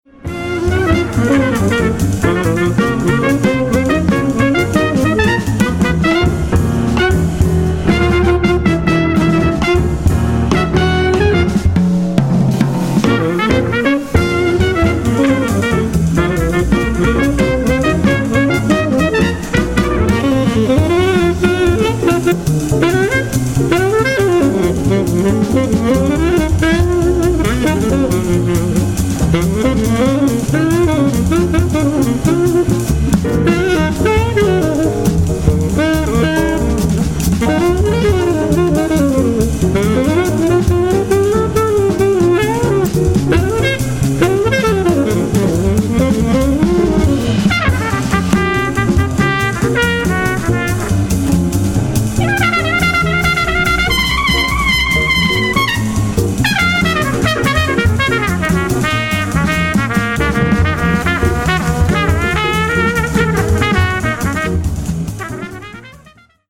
trompette
saxophone ténor
piano
contrebasse
batterie